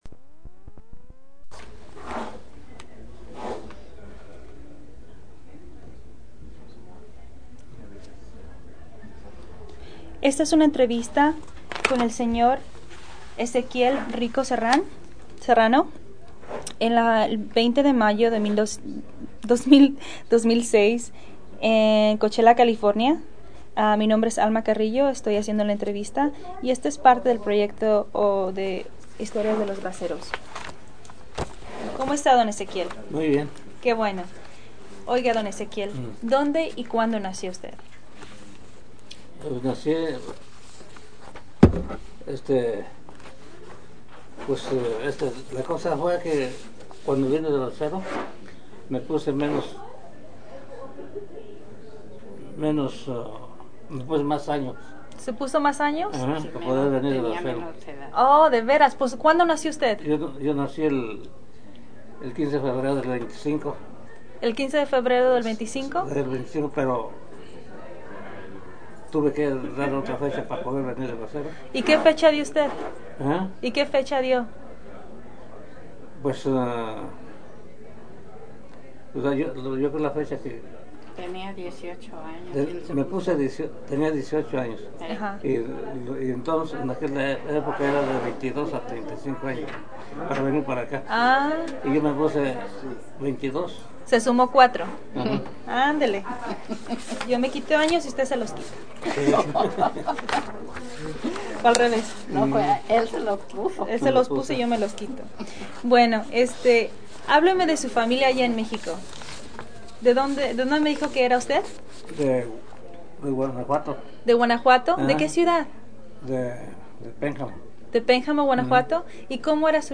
Location Coachella, CA Original Format Mini disc